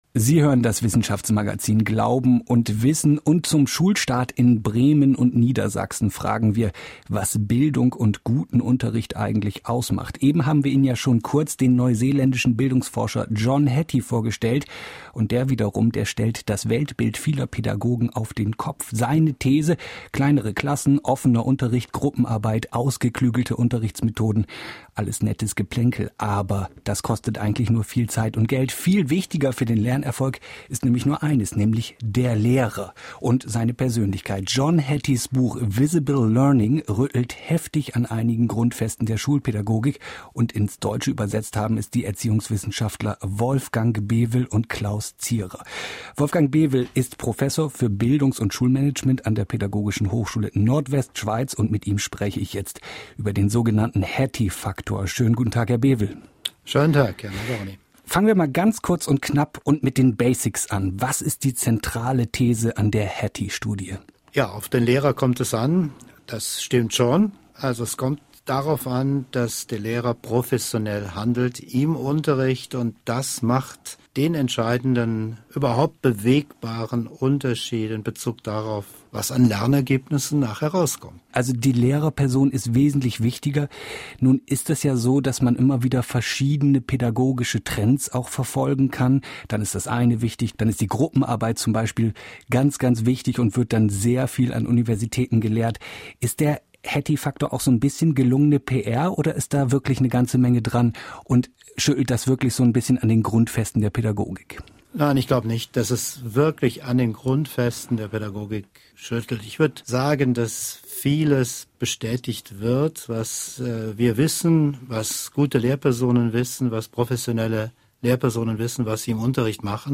Der Hattie-Faktor - Gespräch